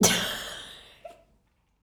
LAUGH 1.wav